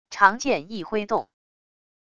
长剑一挥动wav音频